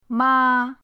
ma1.mp3